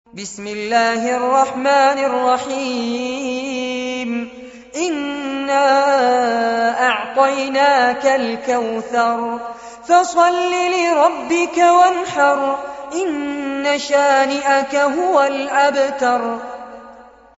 سورة الكوثر- المصحف المرتل كاملاً لفضيلة الشيخ فارس عباد جودة عالية - قسم أغســـــل قلــــبك 2
القرآن الكريم وعلومه     التجويد و أحكام التلاوة وشروح المتون